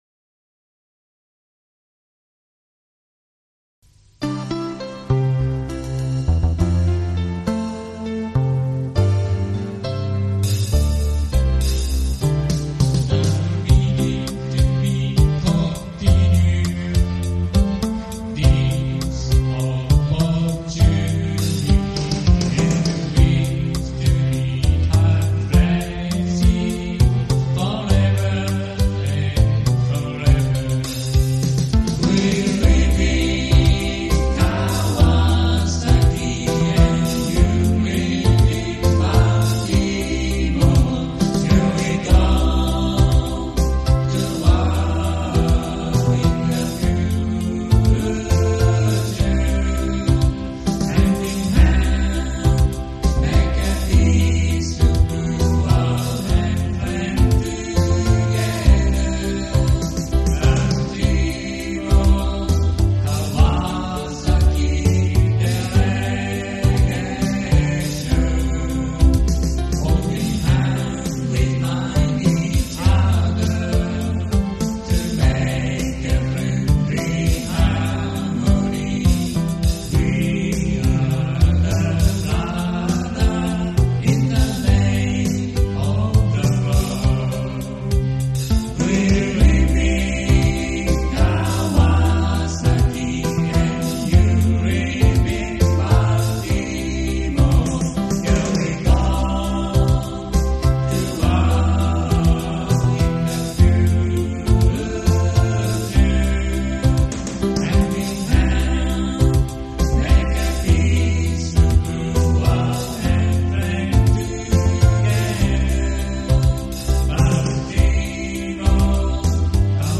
This heartfelt song captures the spirit of unforgettable experiences: camping at the SAJ Yamanaka campsite—now a place of fond memories—and at Broad Creek Scout Reservation, owned by the Baltimore Area Council, as well as the exhilarating challenge of climbing Mt. Fuji.
It was sung with deep emotion, etched into the hearts of those who took part in these meaningful programs.